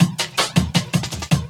12 LOOP12 -R.wav